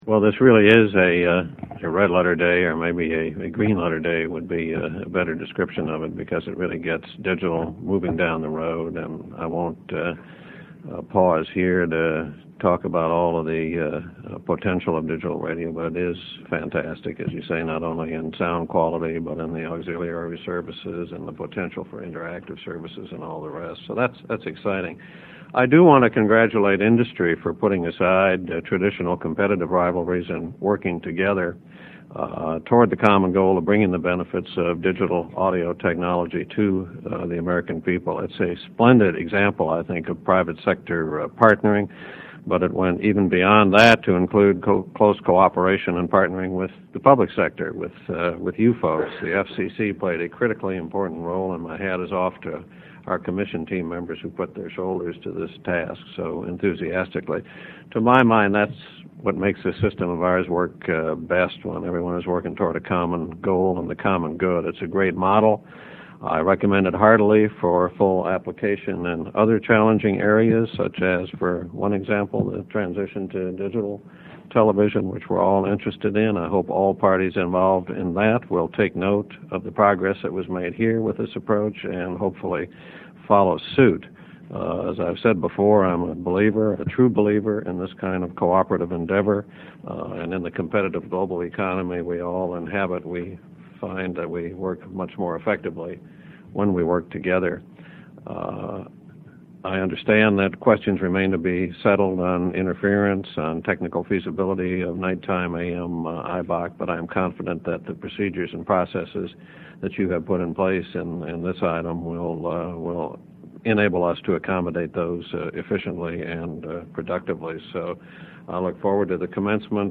Note: All audio is in 48kbps/44KHz mono MP3 format.
FCC Commissioner Michael Copps (2:25, 855K)